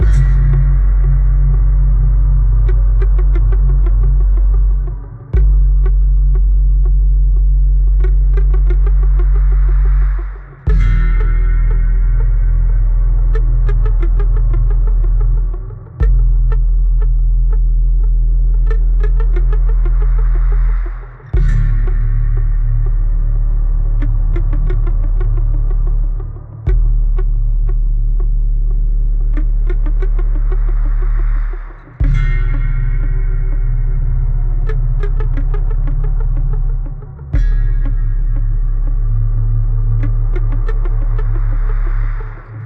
the library soundtrack